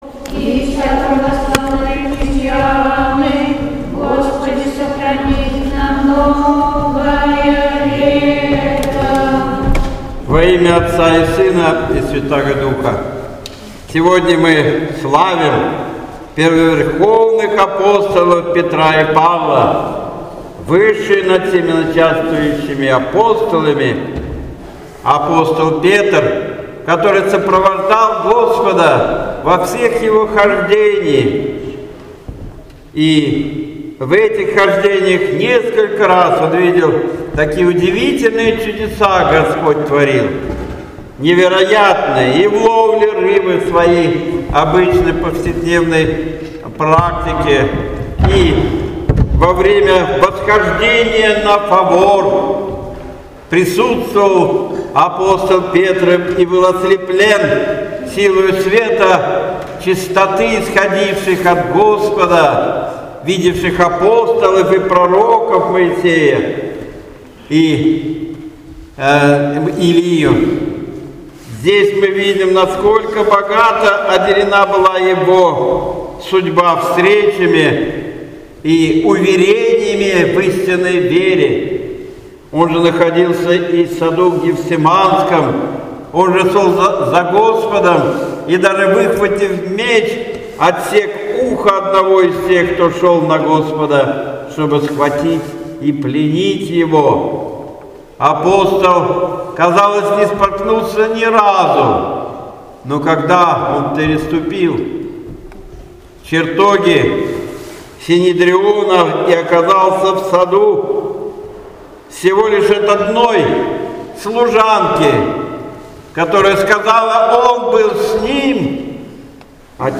Богослужение в день Святых Первоверховных